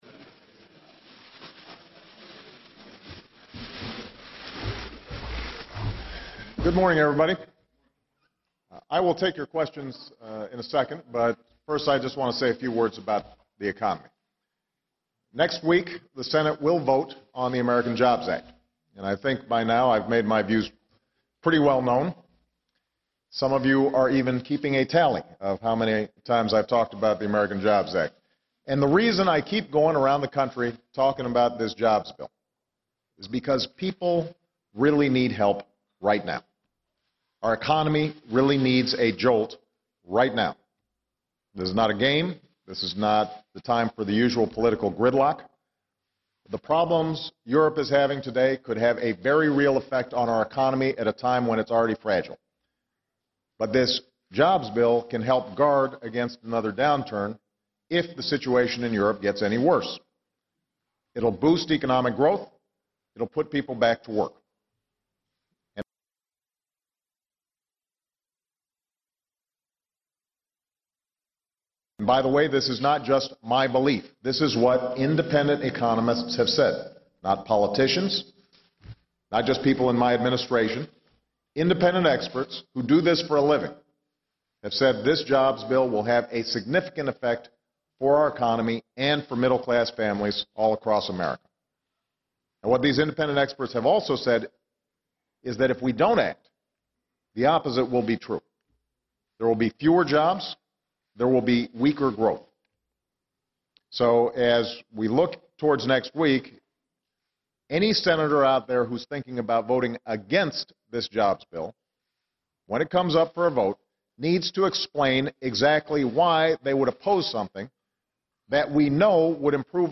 U.S. Presdient Barack Obama holds a press conference on his job plan